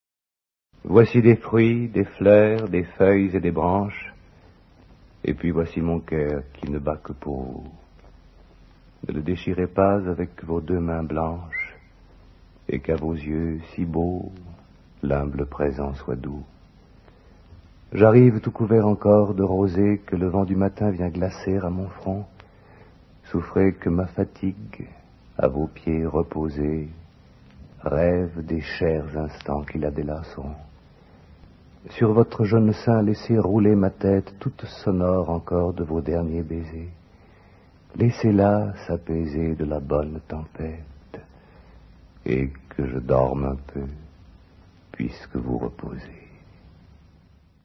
dit par Jean-Claude PASCAL